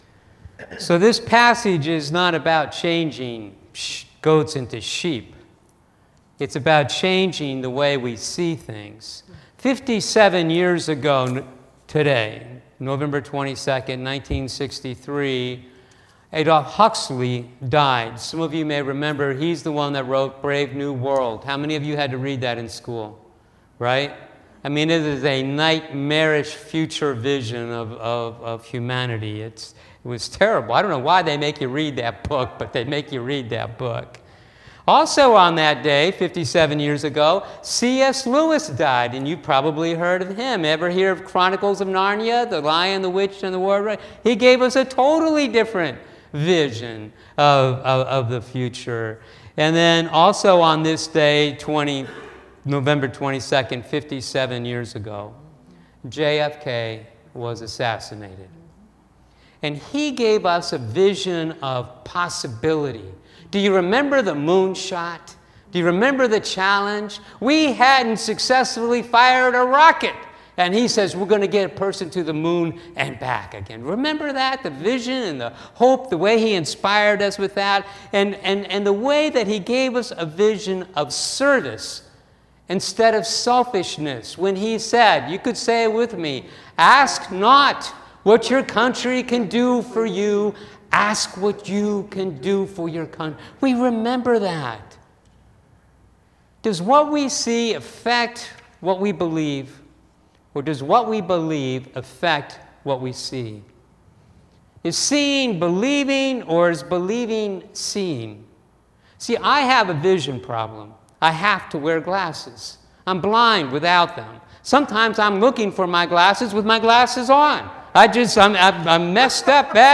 Online Sunday Service